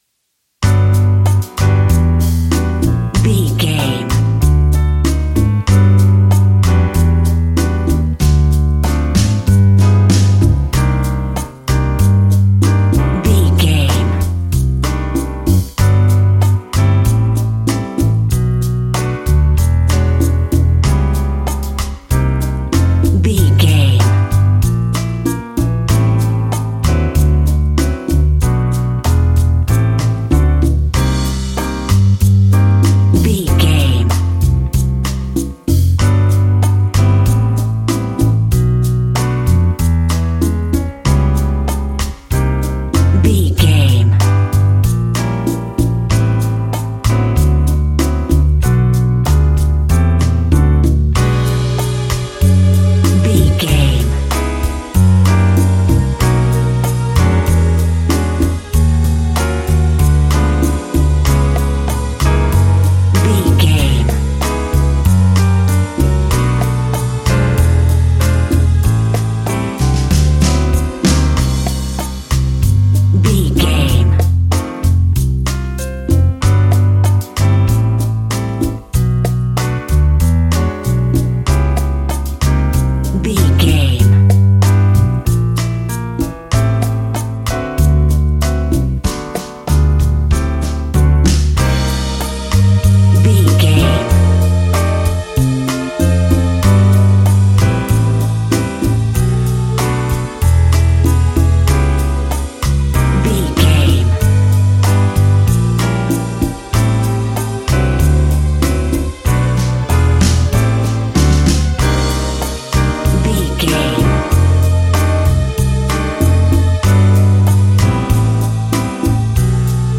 An exotic and colorful piece of Espanic and Latin music.
Ionian/Major
Slow
flamenco
maracas
percussion spanish guitar
latin guitar